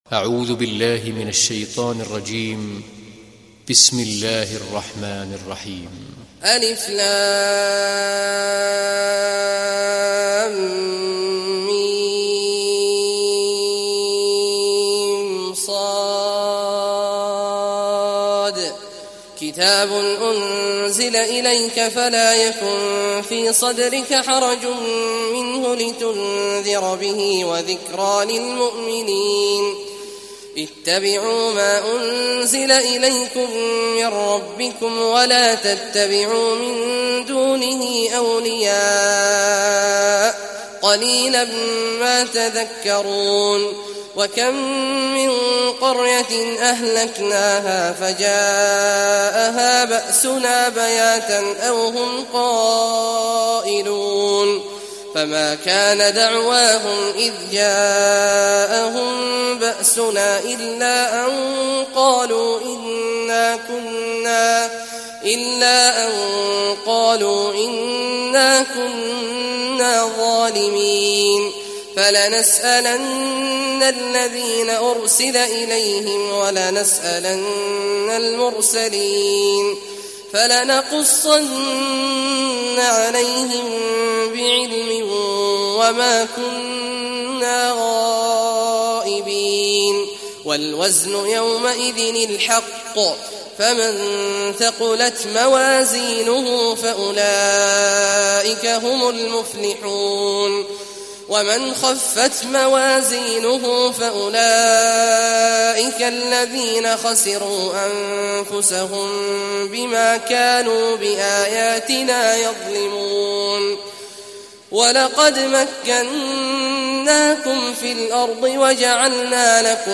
Sourate Al Araf Télécharger mp3 Abdullah Awad Al Juhani Riwayat Hafs an Assim, Téléchargez le Coran et écoutez les liens directs complets mp3